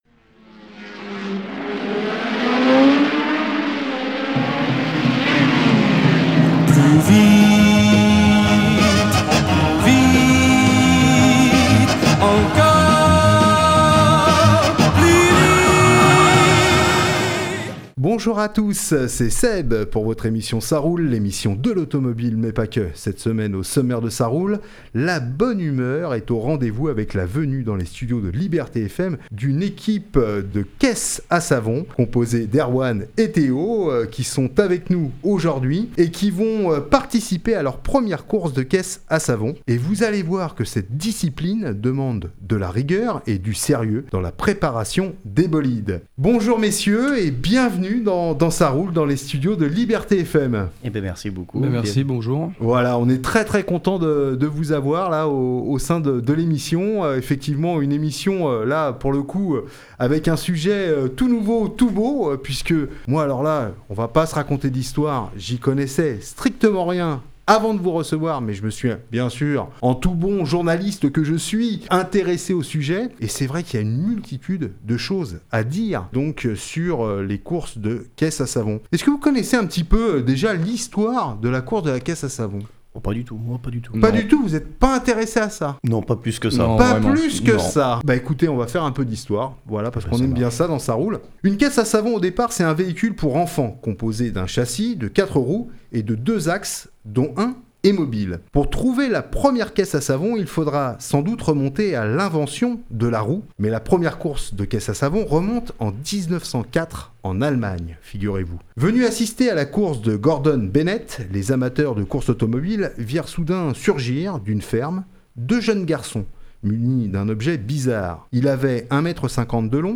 avec nos 2 invités dans les studios de Liberté FM